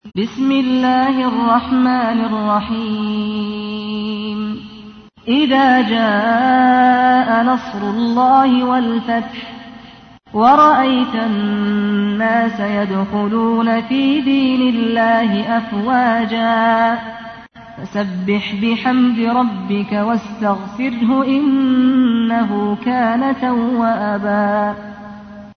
تحميل : 110. سورة النصر / القارئ سعد الغامدي / القرآن الكريم / موقع يا حسين